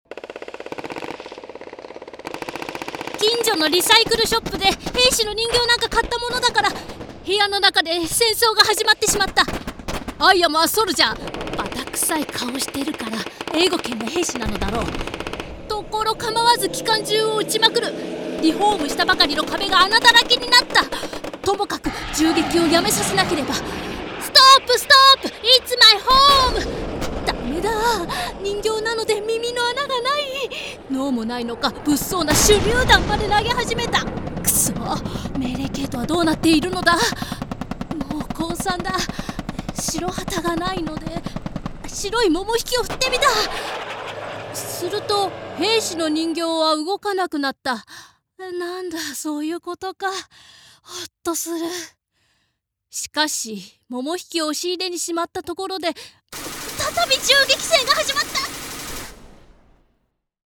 朗読：
BGM・効果音：【効果音ラボ】様「戦闘」”戦闘［2］”